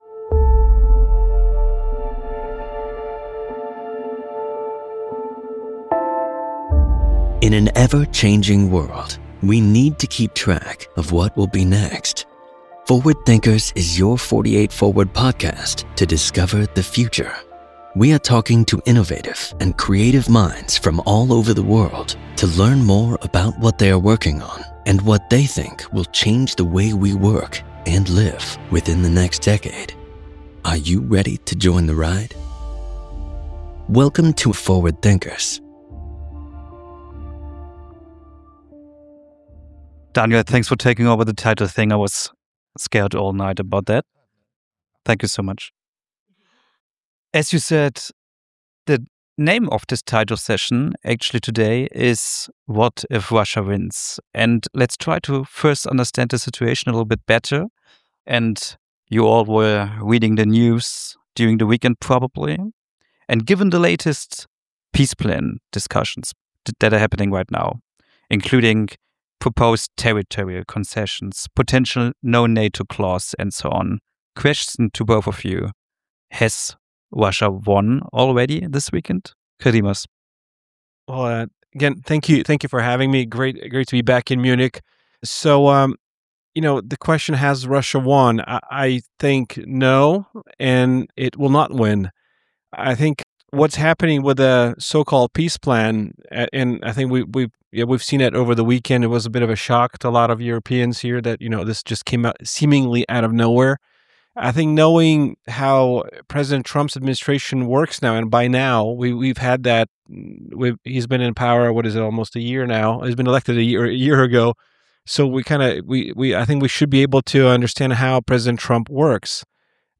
beim 48forward Festival 2025